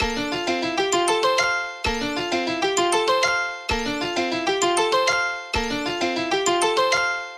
炒菜07
描述：锅里炒菜，做个好饭菜。索尼PCMD50
标签： 厨师长 厨师 烹饪 油炸 出锅 stiry炒 蔬菜
声道立体声